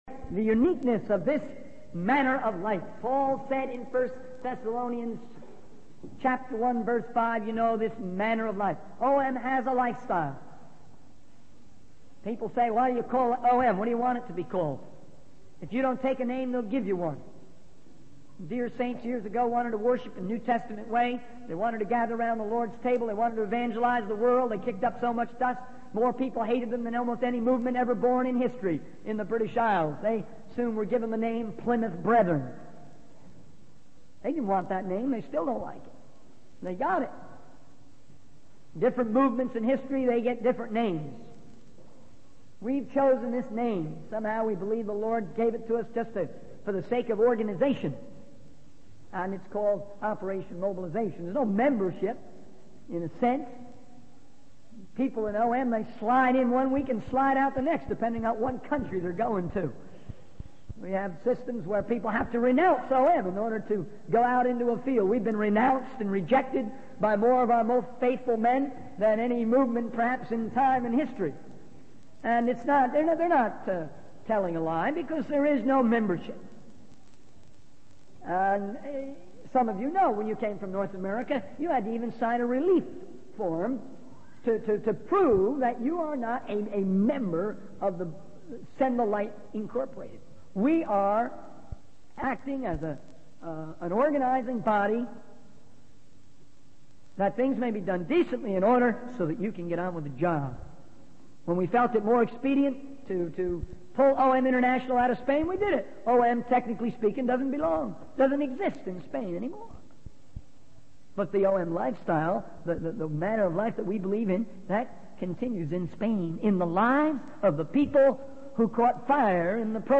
In this sermon, the speaker discusses the need for a revolutionary attitude in the Christian life.